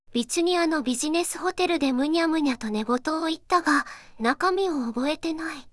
voicevox-voice-corpus
voicevox-voice-corpus / ROHAN-corpus /四国めたん_セクシー /ROHAN4600_0029.wav